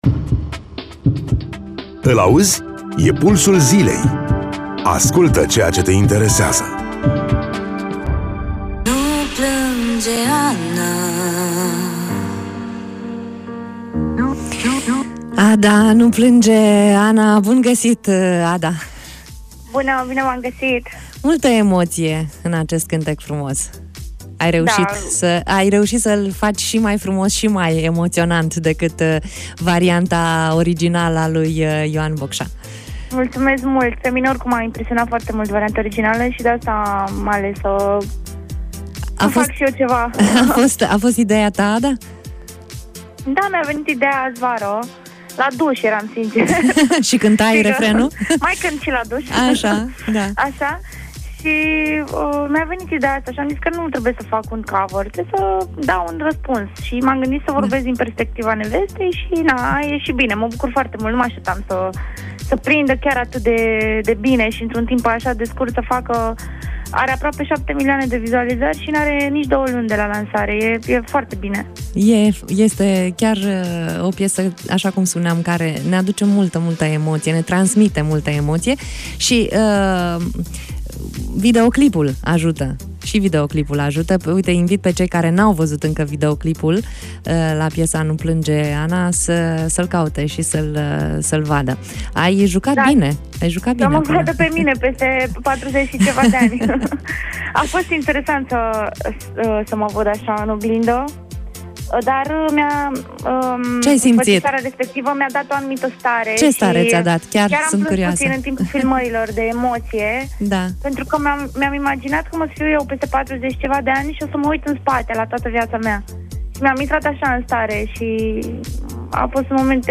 Interviu-Adda.mp3